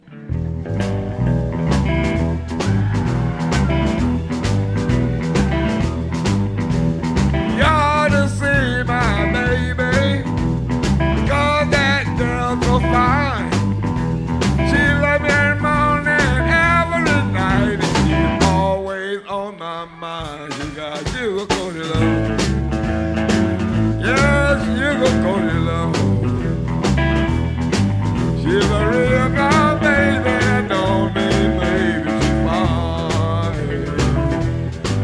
a two disc fully live CD recorded in Santa Cruz, California